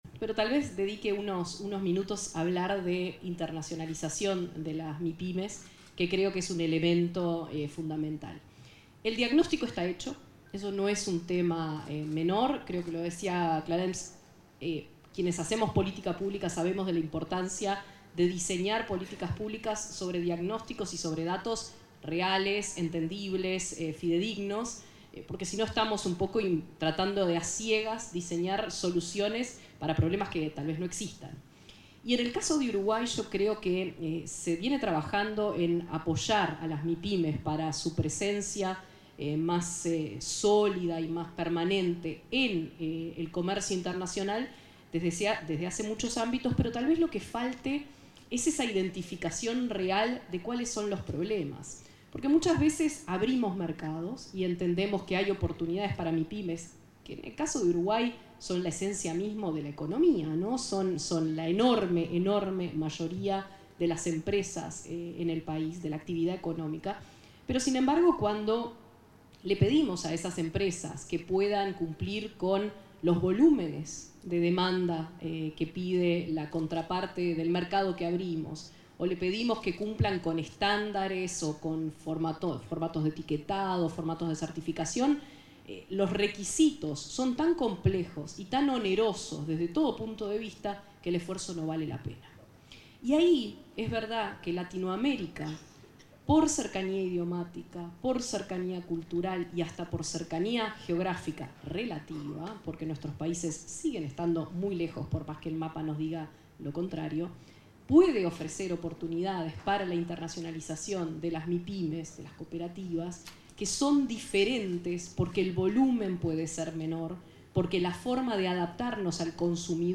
Palabras de ministra de Industria y ministra interina de Relaciones Exteriores
Palabras de ministra de Industria y ministra interina de Relaciones Exteriores 10/07/2025 Compartir Facebook Twitter Copiar enlace WhatsApp LinkedIn La ministra de Industria, Energía y Minería, Fernanda Cardona y su par interina de Relaciones Exteriores, Valeria Csukasi, se expresaron durante el lanzamiento de la Red de Mejores Prácticas de Políticas para Pymes de América Latina y el Caribe.